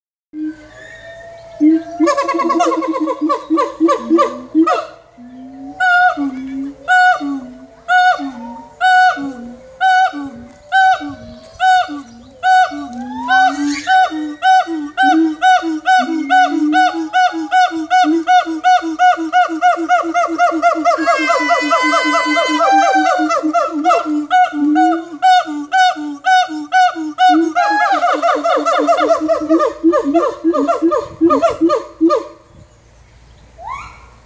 큰긴팔원숭이의 울음소리 녹음